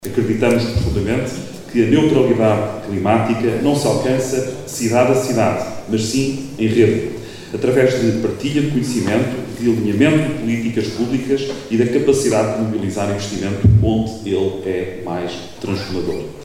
Por fim, Álvaro Santos, Presidente da CCDR-Norte, destacou as vulnerabilidades da região e a importância da cooperação. Para o responsável, a partilha de boas práticas entre autarquias é o pilar para um investimento público transformador.